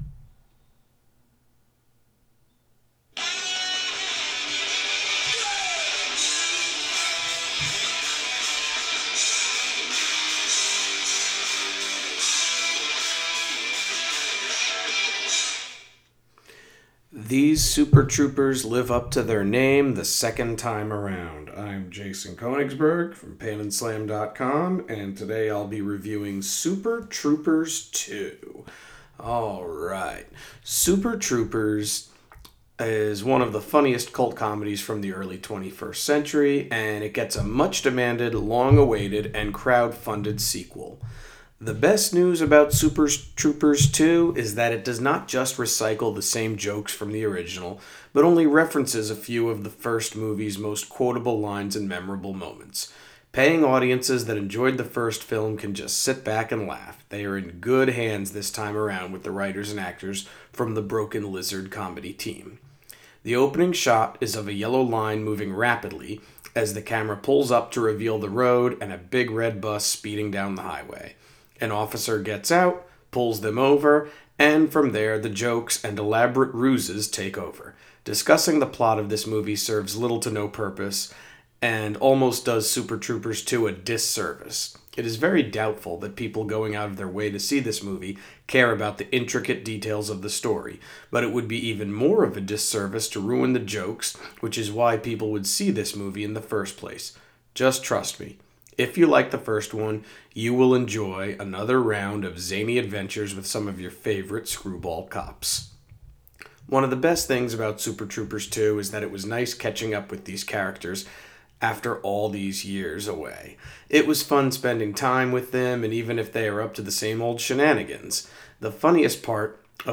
Movie Review: Super Troopers 2